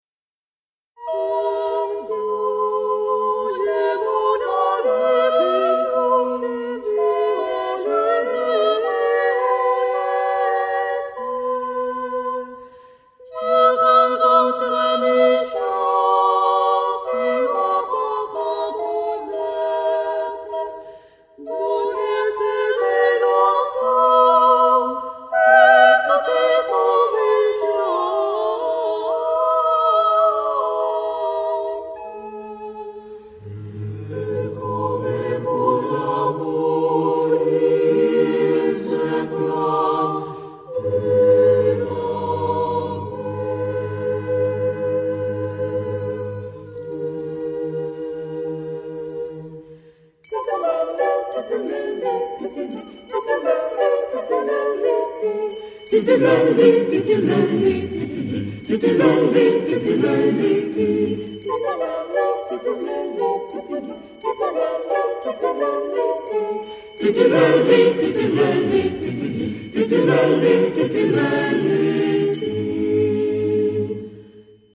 ·         Muestra la amplia gama de géneros que interpreta el coro.